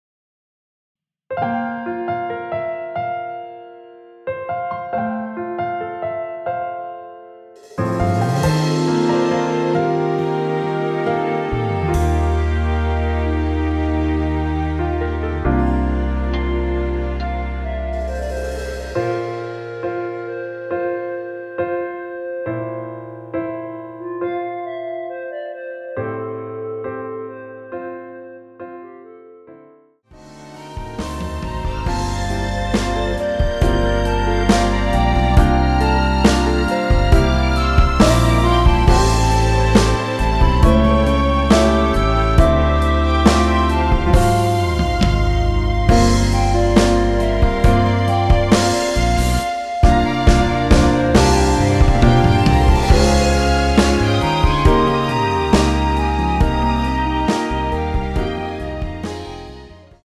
원키에서(+2)올린 1절후 후렴으로 진행 되는 멜로디 포함된 MR입니다.(미리듣기 확인)
앞부분30초, 뒷부분30초씩 편집해서 올려 드리고 있습니다.
중간에 음이 끈어지고 다시 나오는 이유는